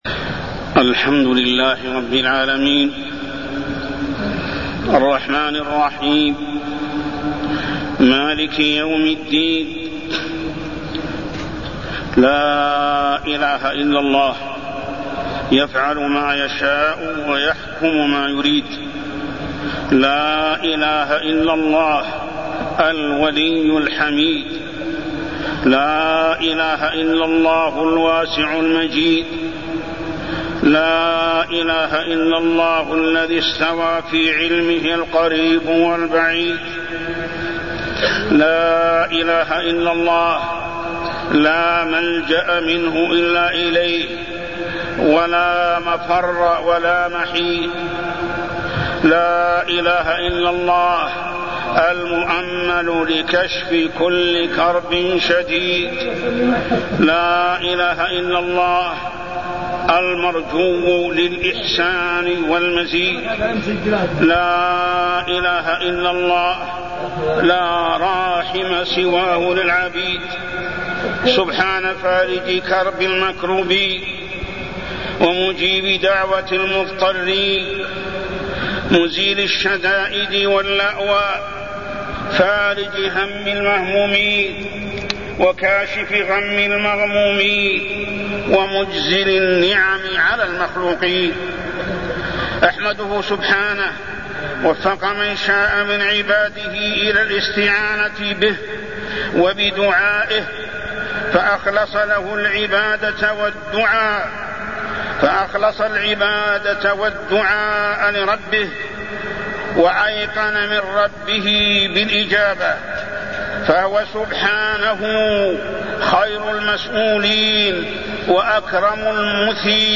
القراءة في صلاة الإستسقاء
تاريخ النشر ١٠ شوال ١٤٢٠ هـ المكان: المسجد الحرام الشيخ: محمد بن عبد الله السبيل محمد بن عبد الله السبيل القراءة في صلاة الإستسقاء